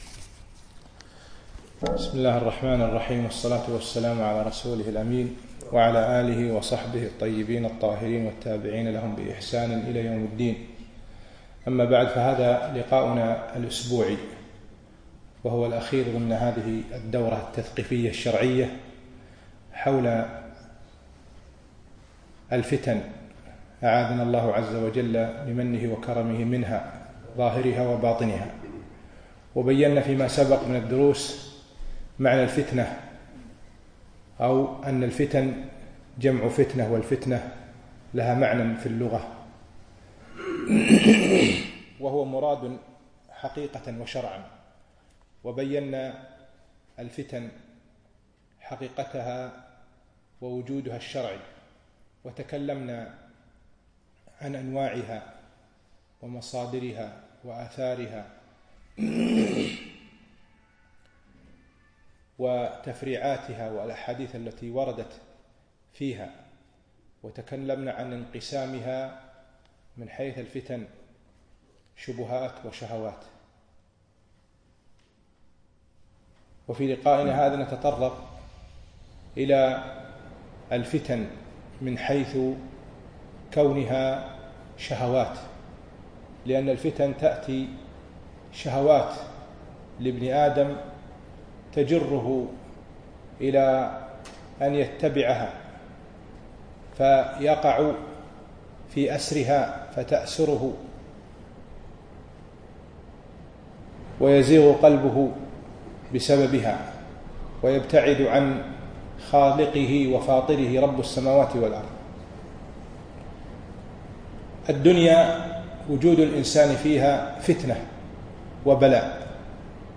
المحاضرة الثامنة - فتن الشهوات